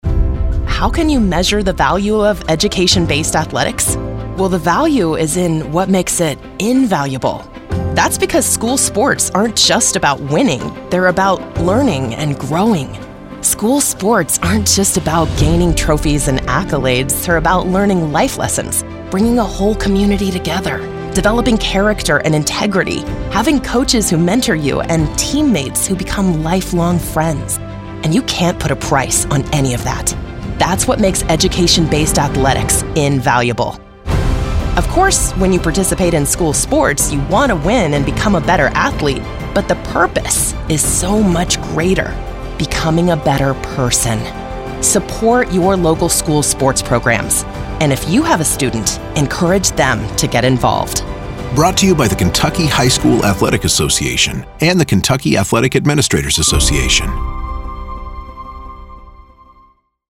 24-25 Radio – Public Service Announcements